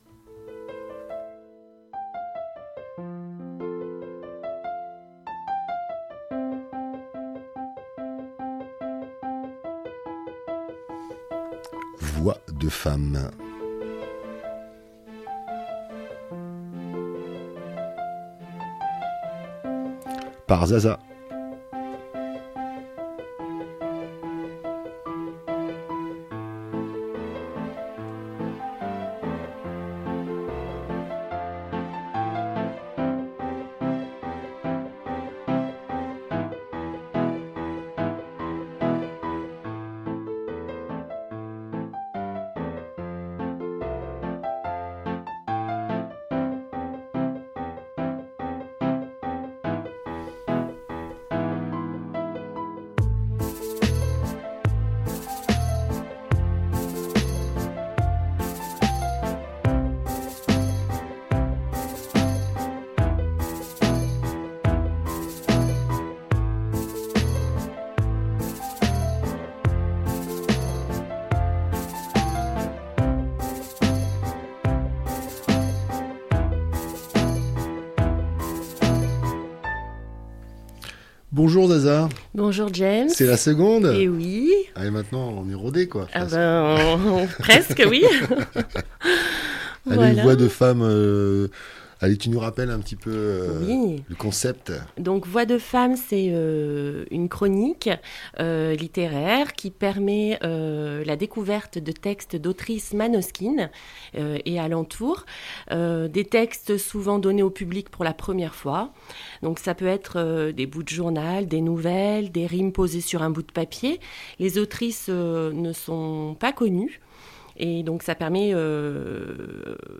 Voix de femmes